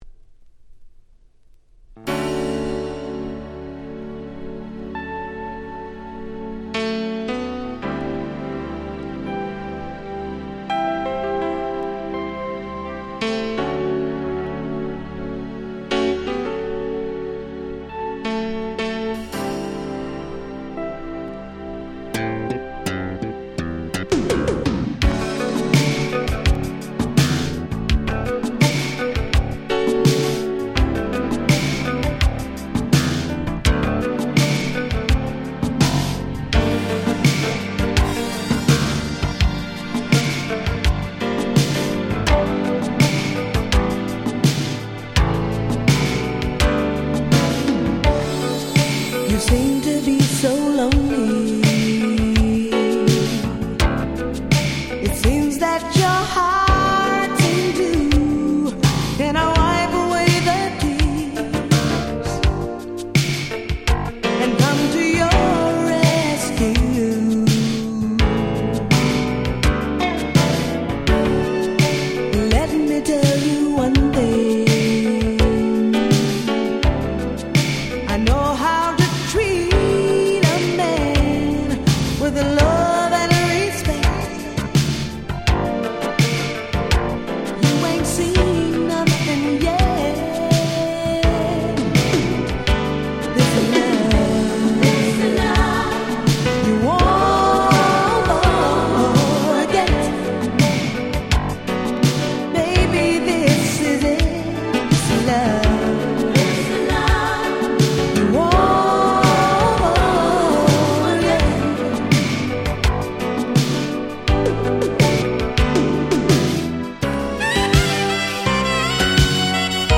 もう超〜良いMidナンバー！！
80's R&B